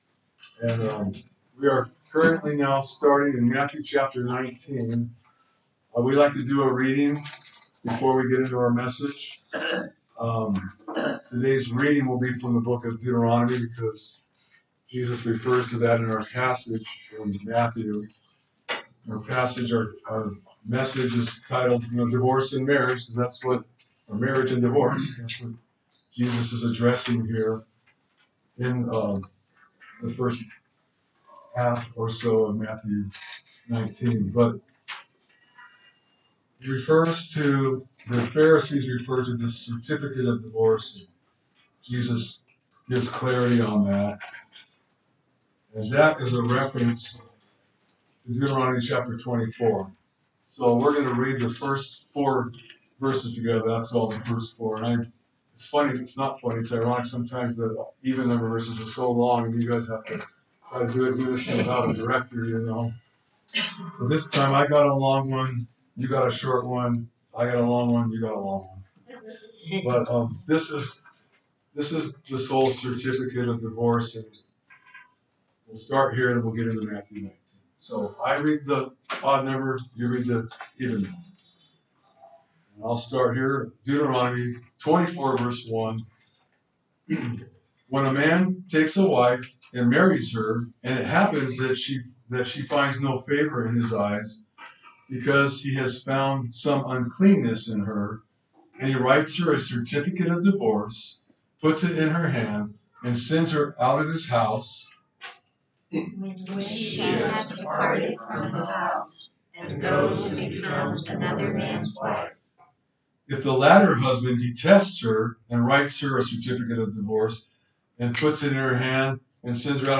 A message from the series "Matthew."